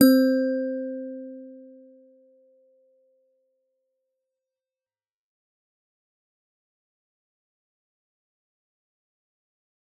G_Musicbox-C4-f.wav